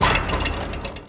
metal_gate_fence_impact_02.wav